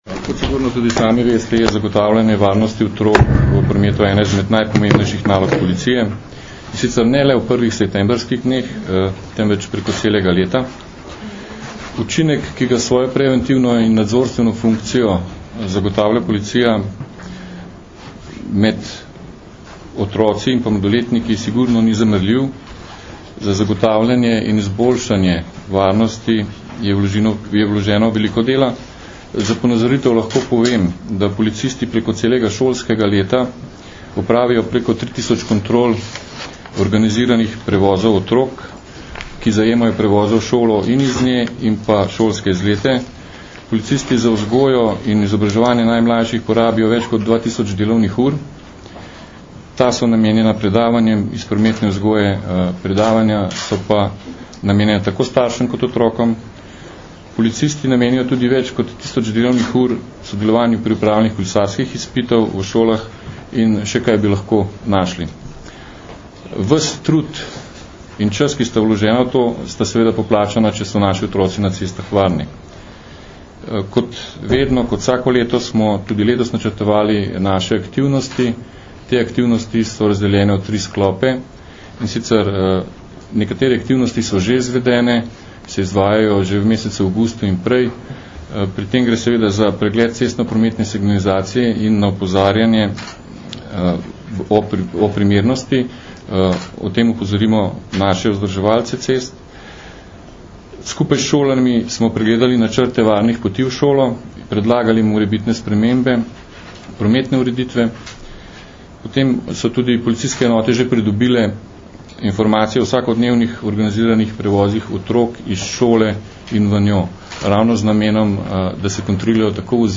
Na današnji novinarski konferenci na Ministrstvu za šolstvo in šport so predstavniki šolskega ministrstva, Direktorata za promet na Ministrstvu za promet, Sveta za preventivo in vzgojo v cestnem prometu ter policije skupaj predstavili svoje preventivne aktivnosti v cestnem prometu ob začetku šolskega leta.